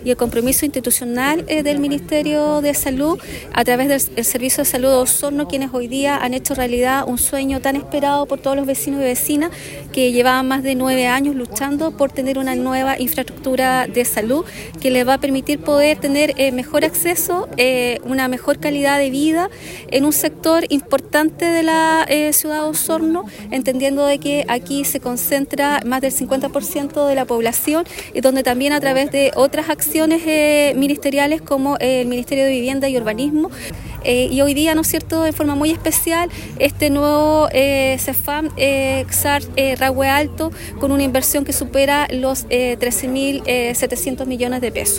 La Delegada Presidencial Provincial, Claudia Pailalef, indicó que esto responde a un compromiso institucional para mejorar la cobertura y el acceso a la salud pública.